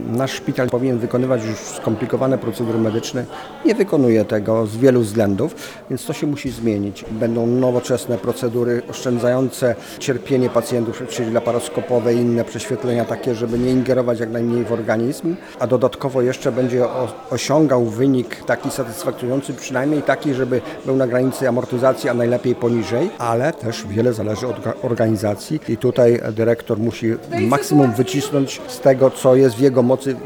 Mówi starosta mielecki, Stanisław Lonczak.